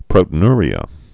(prōtn-rē-ə, -yr-, prōtē-nr-, -nyr-)